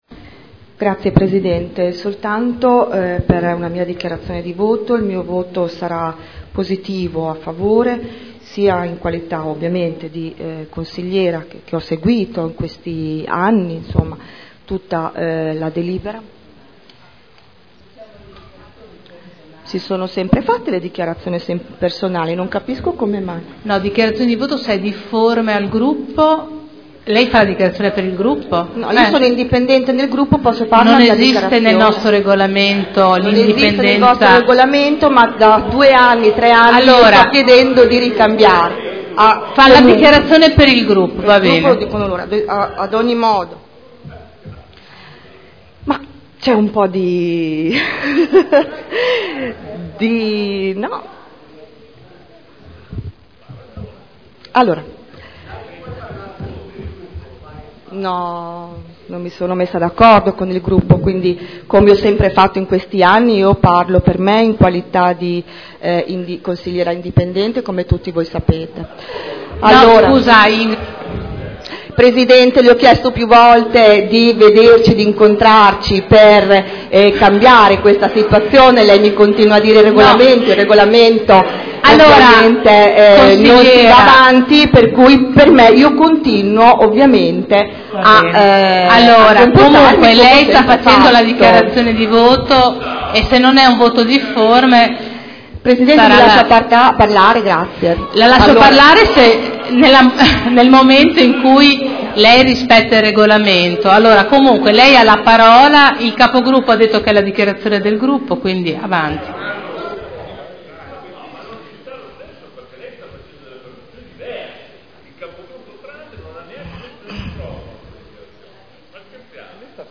Seduta del 26/11/2012 Dichiarazione di voto.